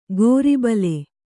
♪ gōri bale